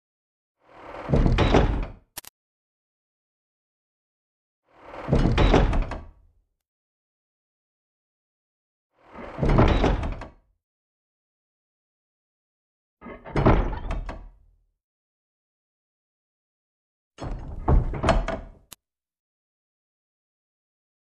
DOOR, DUNGEON DOOR: VERY LARGE, CLOSES QUICKLY, LOCK
• Category: Door Sounds